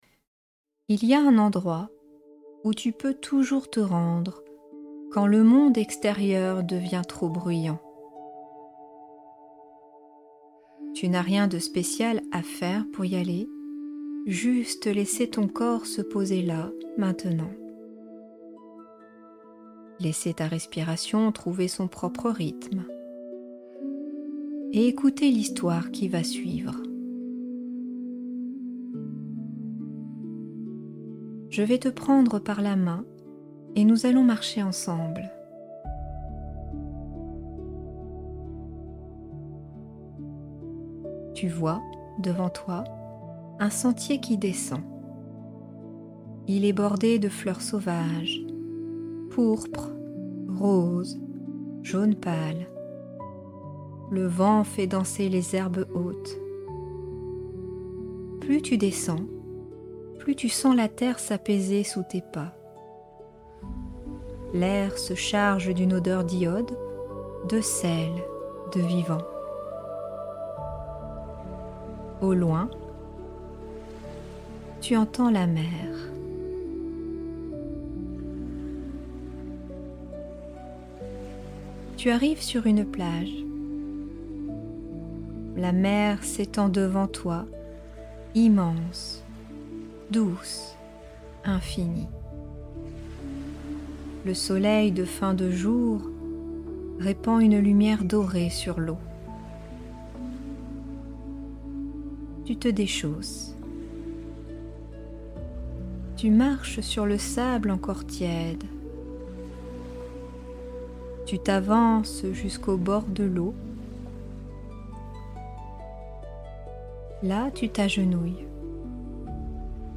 Conte thérapeutique vibratoire · ~10 minutes